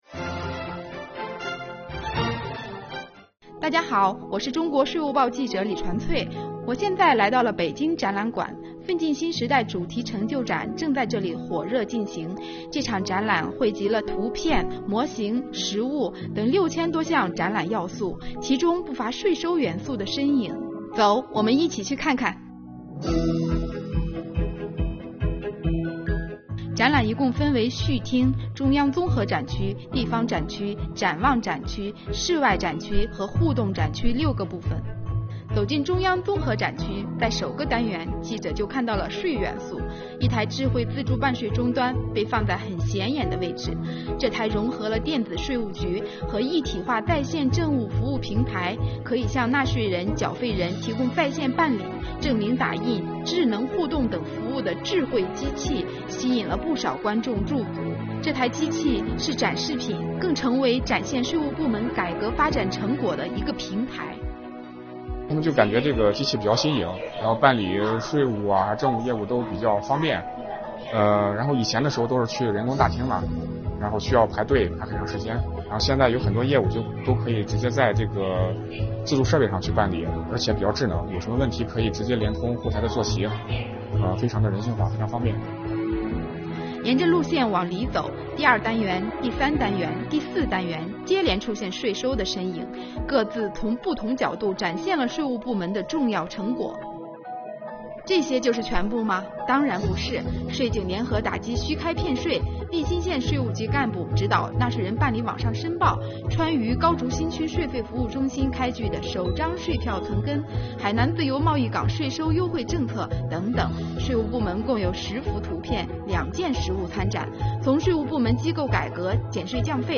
10月12日，受党的二十大新闻中心邀请，本报记者走进北京展览馆，参观并采访“奋进新时代”主题成就展。
快跟记者一起去看看吧！